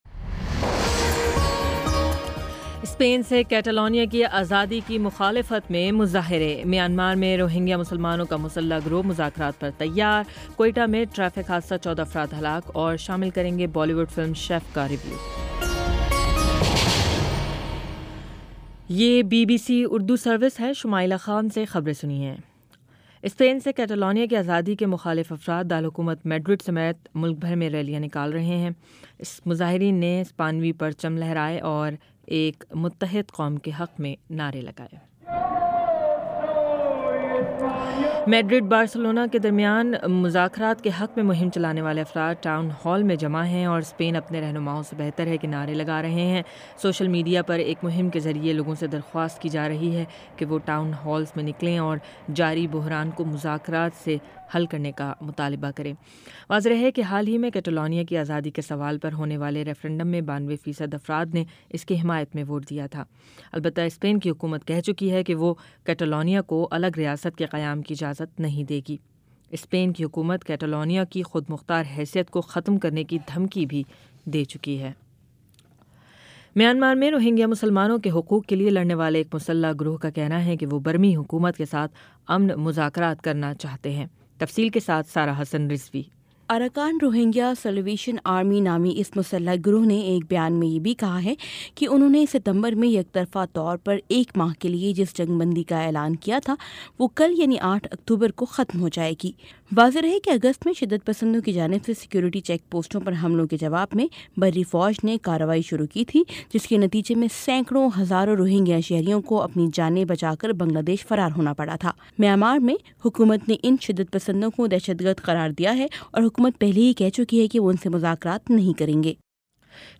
اکتوبر 07 : شام سات بجے کا نیوز بُلیٹن